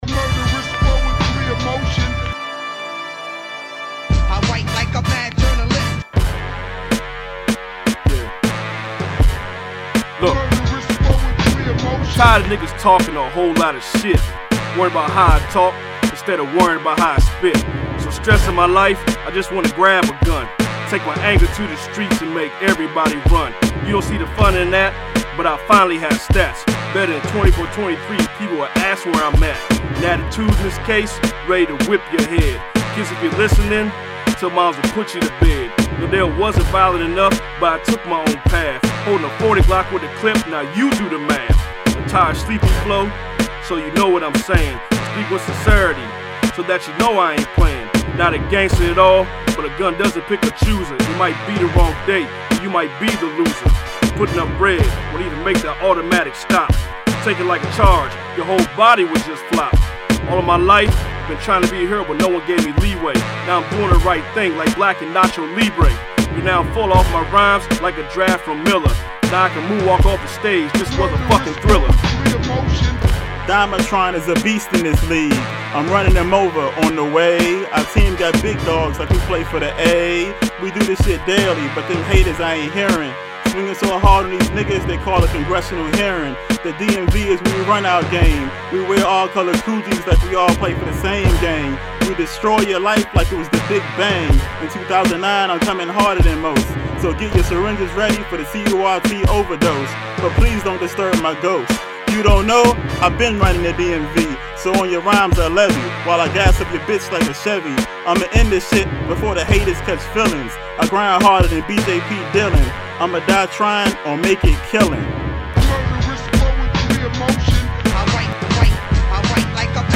Rhymes